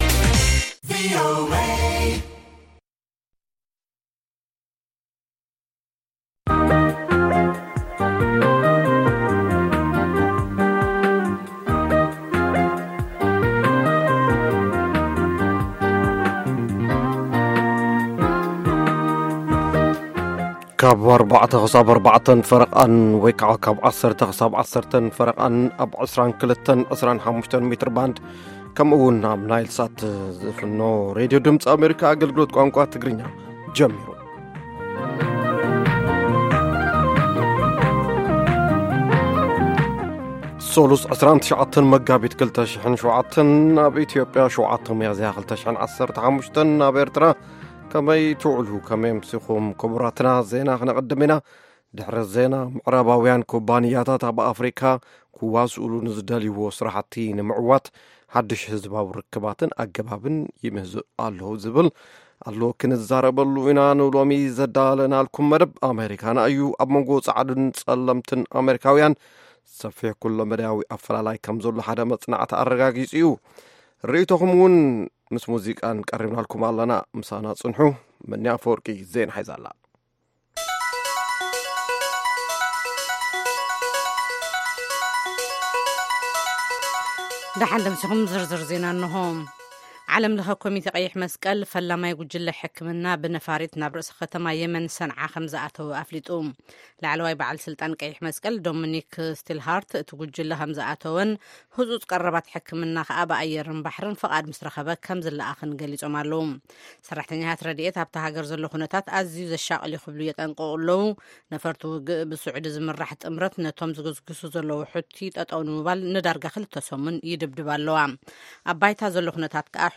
ፈነወ ትግርኛ ብናይ`ዚ መዓልቲ ዓበይቲ ዜና ይጅምር ። ካብ ኤርትራን ኢትዮጵያን ዝረኽቦም ቃለ-መጠይቓትን ሰሙናዊ መደባትን ድማ የስዕብ ። ሰሙናዊ መደባት ሰሉስ፡ ኤርትራውያን ኣብ ኣመሪካ/ ኣመሪካና